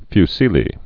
(fy-sēlē, -sĭlē)